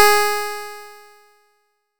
guitarHeroButBetter/nes_harp_Gs4.wav at a0fab24f28b129e7c41af1c7c2c39a91ded6b3f2
nes_harp_Gs4.wav